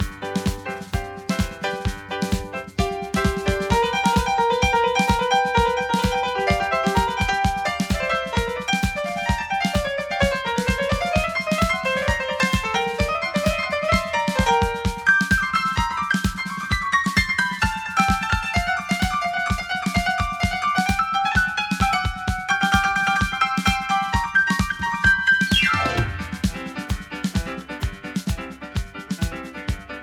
isolated piano solo with just piano & drums